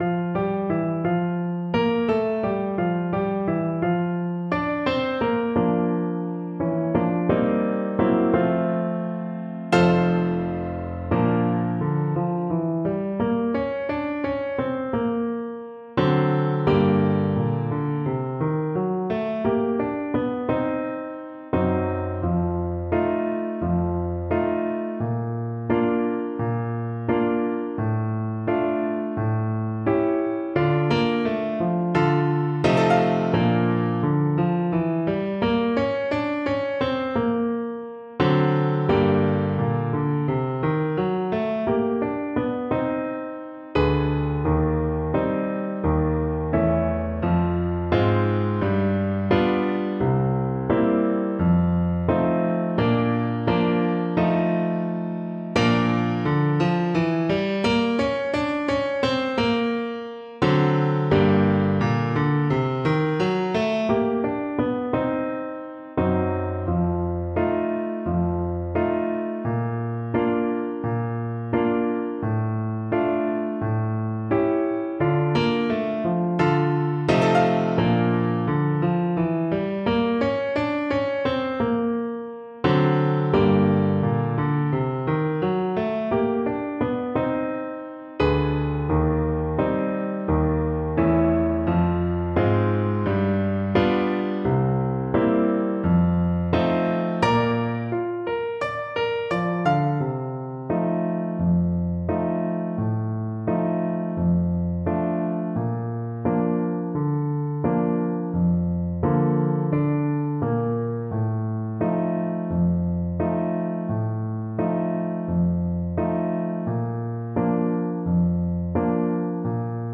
2/4 (View more 2/4 Music)
Slow march tempo = 72